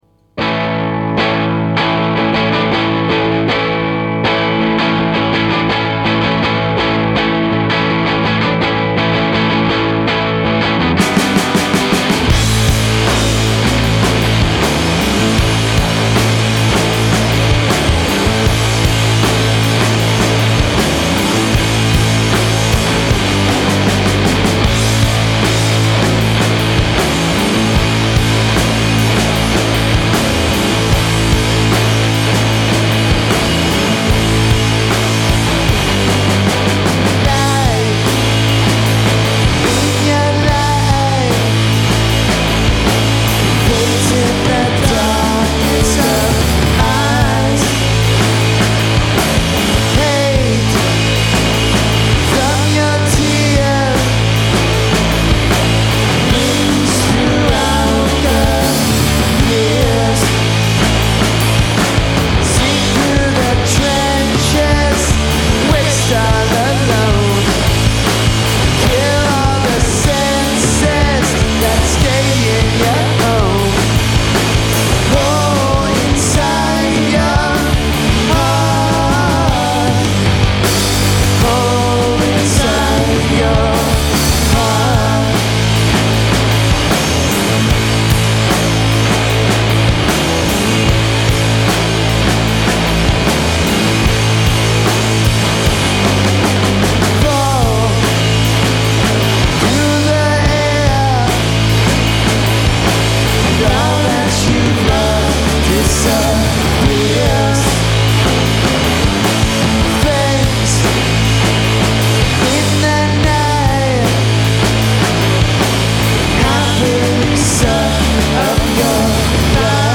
Maida Vale Studios
recorded at the BBC’s Maida Vale studios
noise-damaged freak-rock outfit